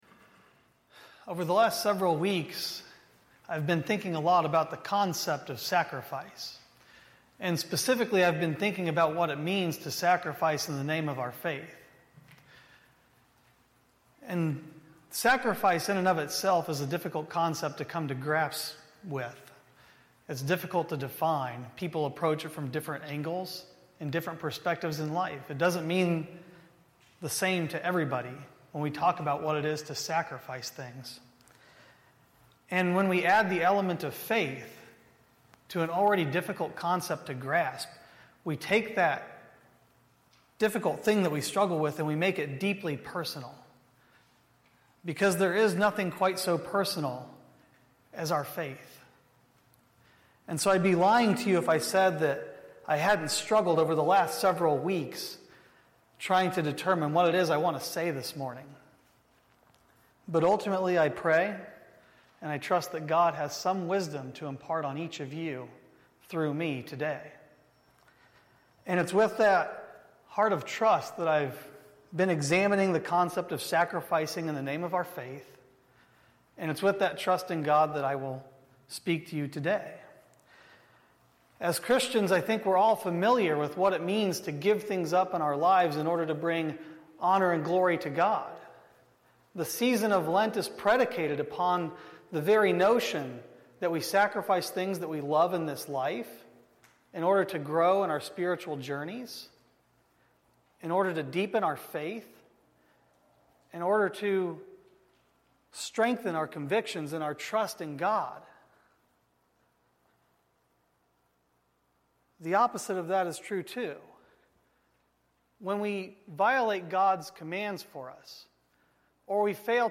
First Baptist Church Rochester Indiana / Sacrafice and Faith, April 2, 2023